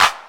Clap 20.wav